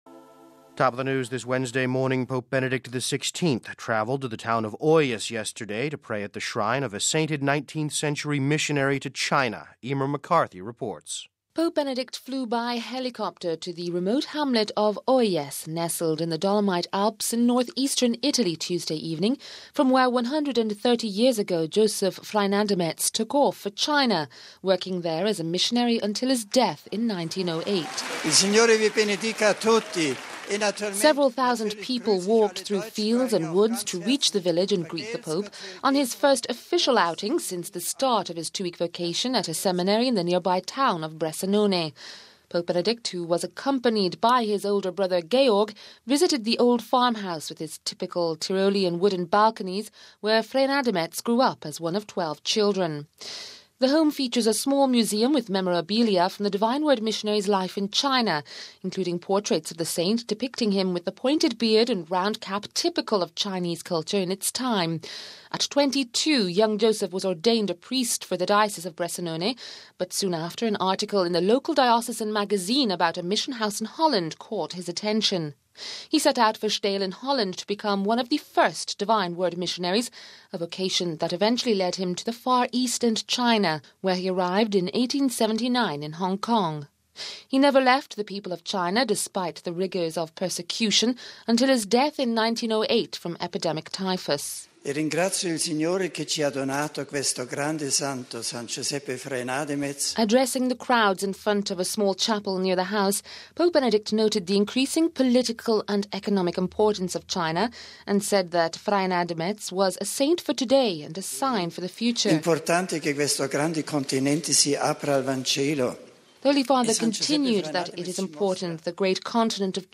Addressing the crowds in front of the small chapel near the house, Pope Benedict noted the increasing political and economic importance of China and said that Freinademetz was “a saint for today and a sign for the future”.
Pope Benedict’s short address was greeted with applause and notes of an Alpine band from the Badia Valley.